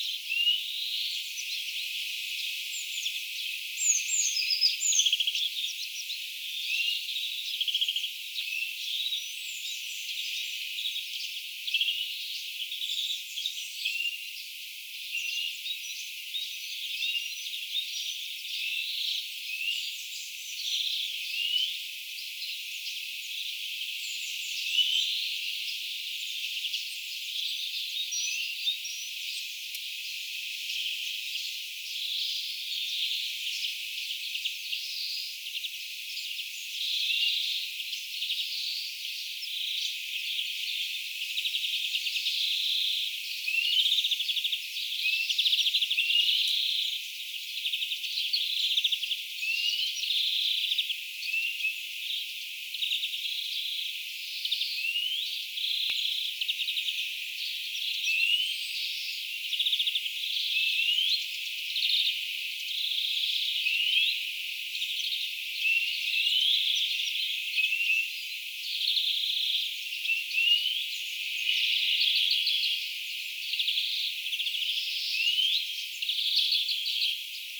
viherpeipon kuin hyit-ääniä
Jotkin äänistä muistuttavat hyit-ääniä?
viherpeipon_vahan_kuin_hyit_aania_ainakin_jotkut_aanista_tuovat_mieleen_ehkapa_pajulinnun.mp3